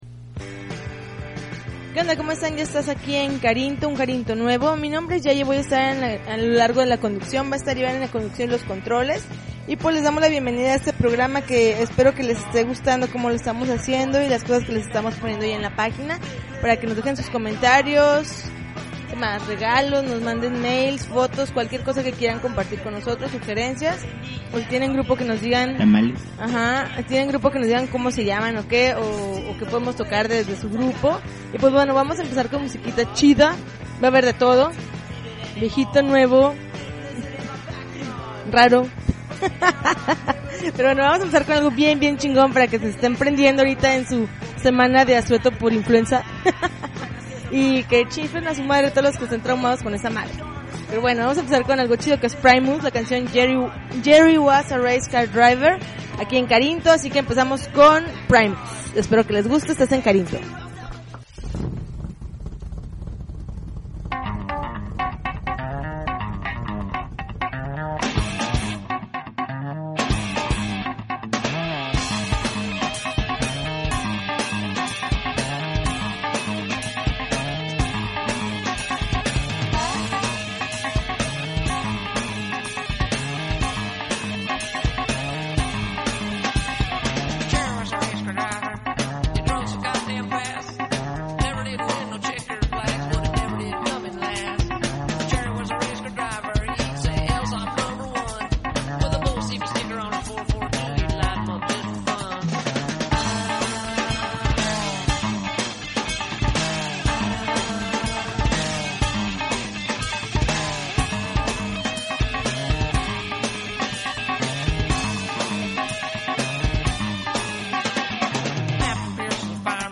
May 3, 2009Podcast, Punk Rock Alternativo